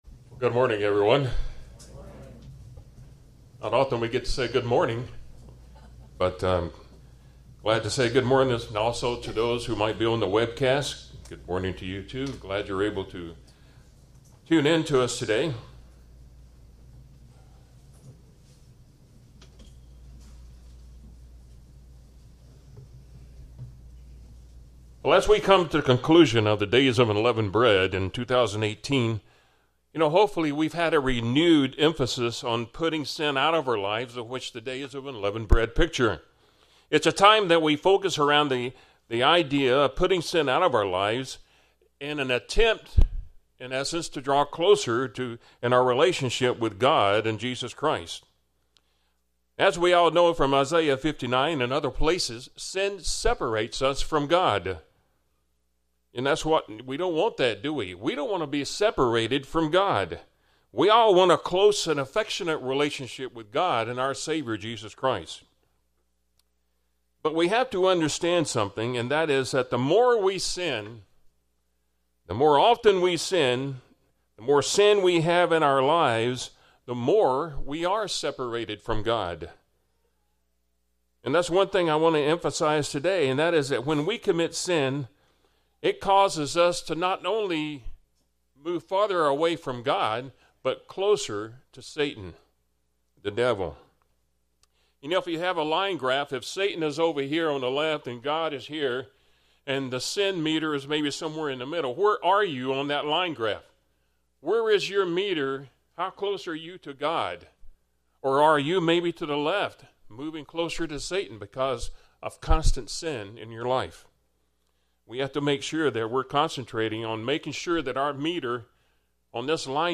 In this Sermon lets examine how we can try and cope with sin and look at several fallacies about trying to cover it up.